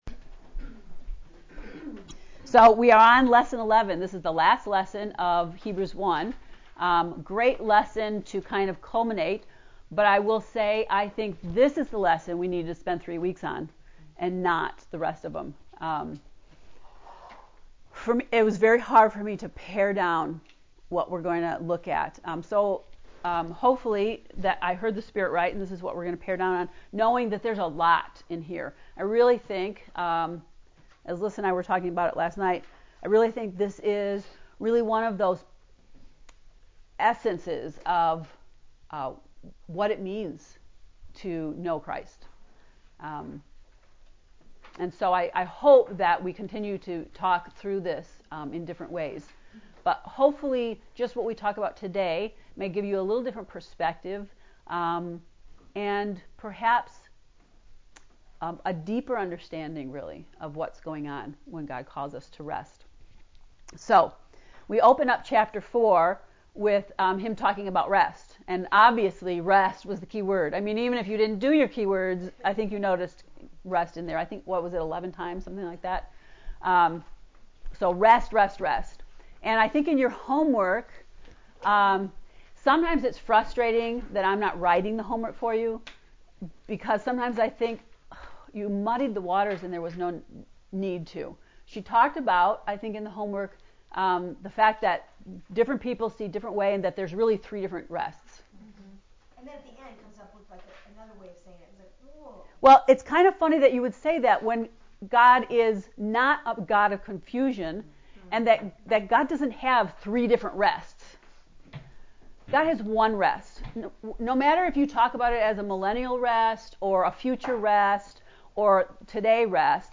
To listen to Hebrews lesson 11 lecture “To Breathe Again”, click below:
heb-lecture-11.mp3